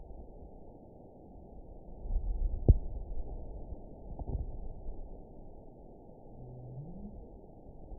event 920562 date 03/30/24 time 03:36:03 GMT (1 year, 1 month ago) score 9.35 location TSS-AB04 detected by nrw target species NRW annotations +NRW Spectrogram: Frequency (kHz) vs. Time (s) audio not available .wav